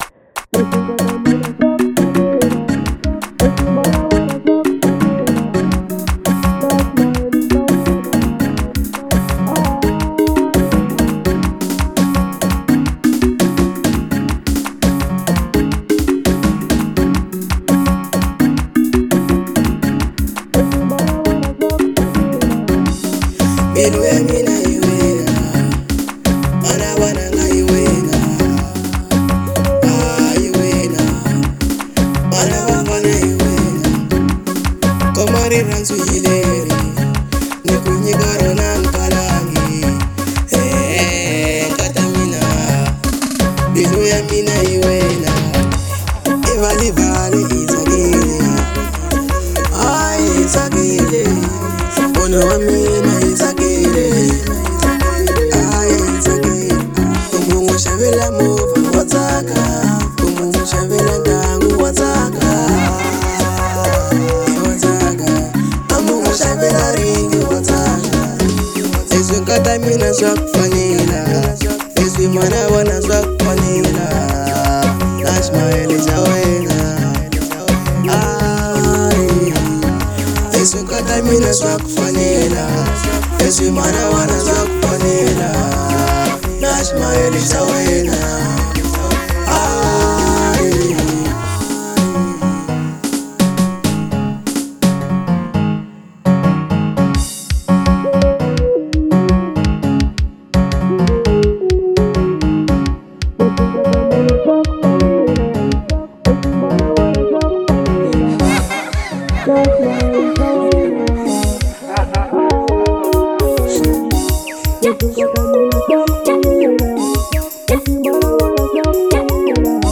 04:05 Genre : Xitsonga Size